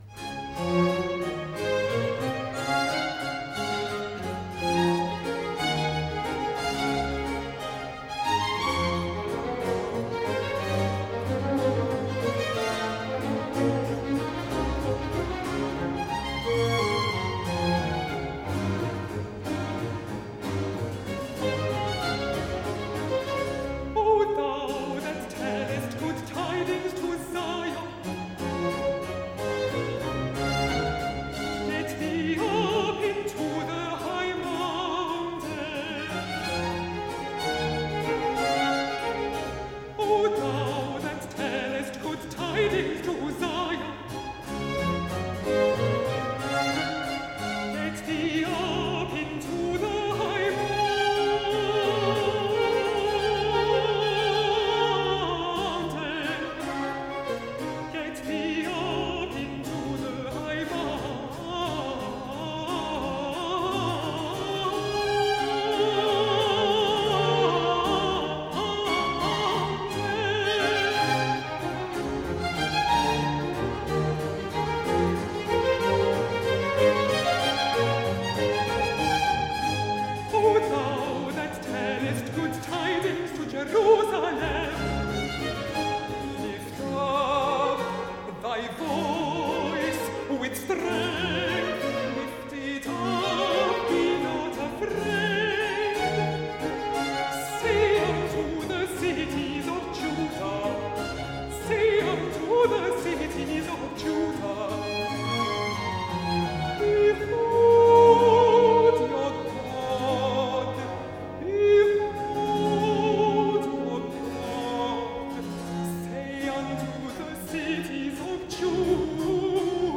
Aria-alto & chorus